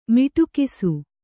♪ miṭukisu